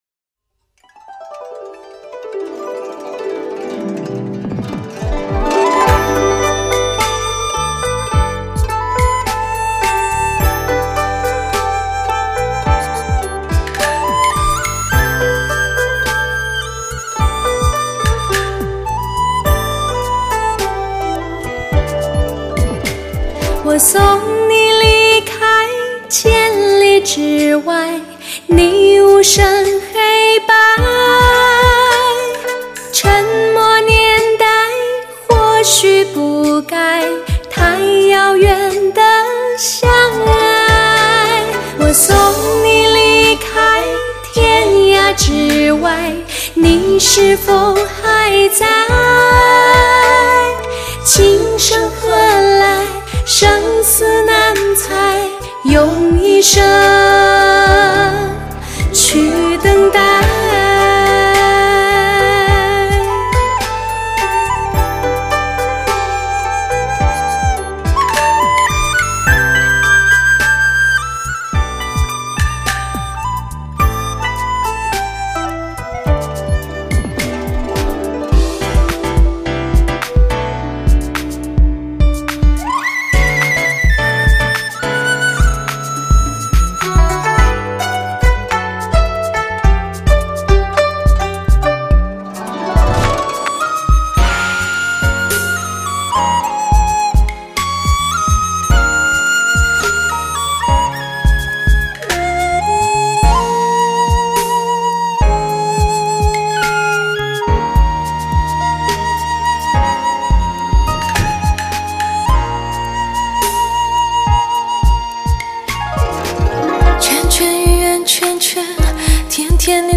采用最新美国DTS-ES 6.1顶级编码，创造超乎想象完美环绕声震撼体验。
高端特制紫水晶CD，音色更加圆润细腻，声场更加雄壮宽广。
柔和而动听的旋律让您仿佛行驶在充满着鲜花与阳光的幸福之路。轻松而愉悦的曲子解除旅途中的疲惫……